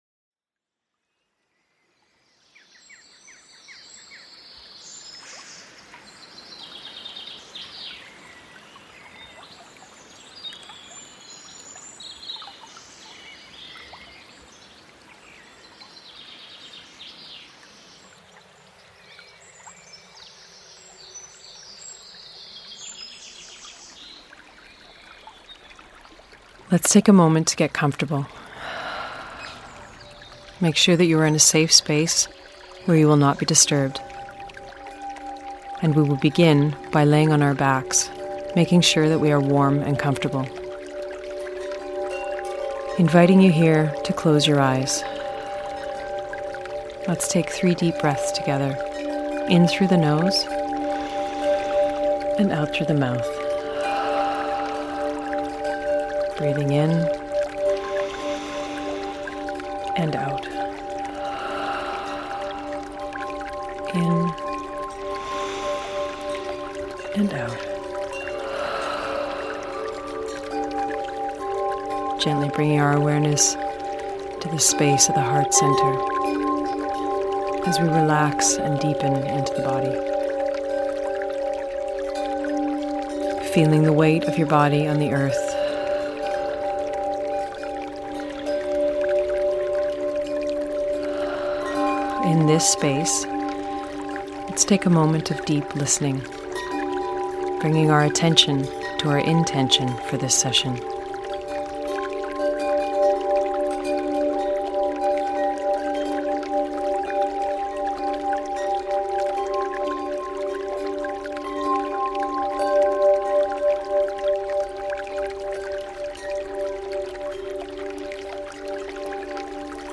A free guided breathwork audio — to take with you, wherever you are.
ancient-fire-breathwork.mp3